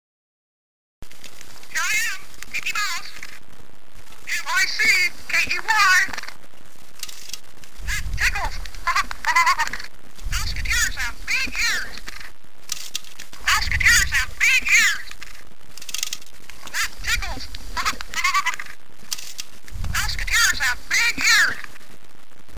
This was actually a series of toys in which you'd pull the string on the character's back and the top of their head would move as they'd spout off some scintillating conversation like this .